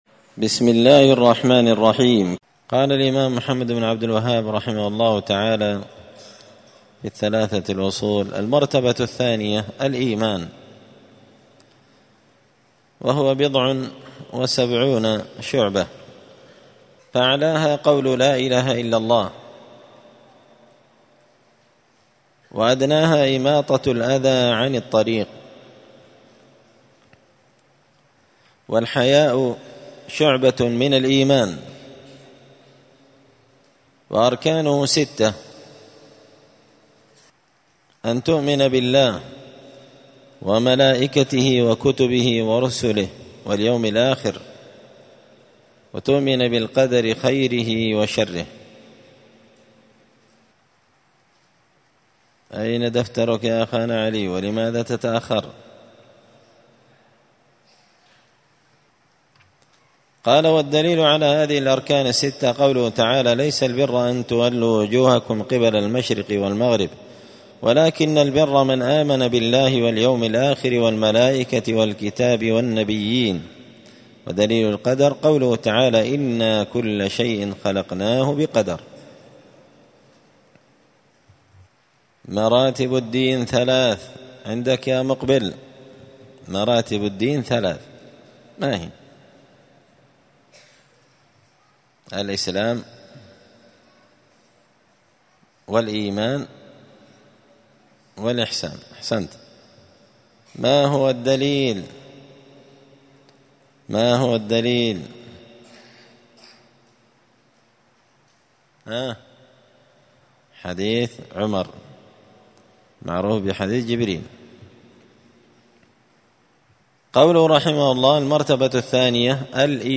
مسجد الفرقان قشن_المهرة_اليمن
الأربعاء 29 جمادى الأولى 1445 هــــ | الدروس، حاشية الأصول الثلاثة لابن قاسم الحنبلي، دروس التوحيد و العقيدة | شارك بتعليقك | 75 المشاهدات